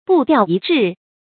讀音讀法：
步調一致的讀法